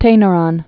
(tānə-rôn, tĕnä-), Cape Formerly Cape Mat·a·pan (mătə-păn)